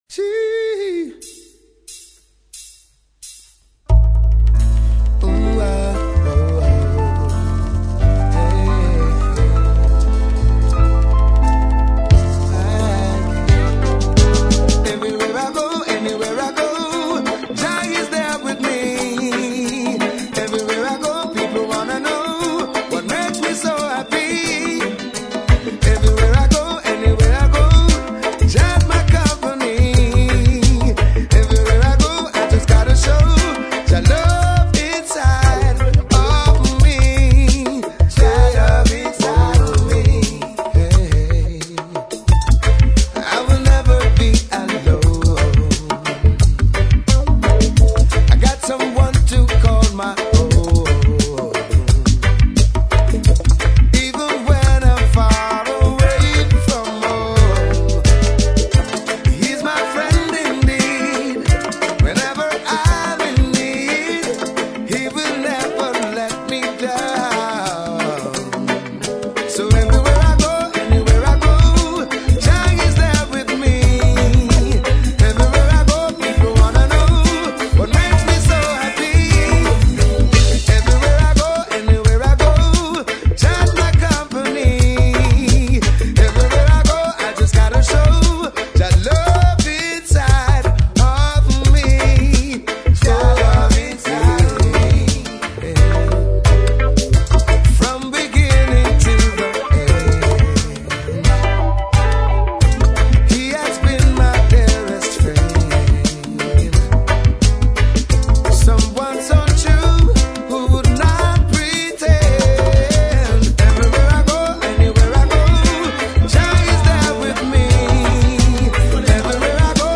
Cette production New Roots 100% originale
Guitare
Percussion
Keyboards
Backing vocals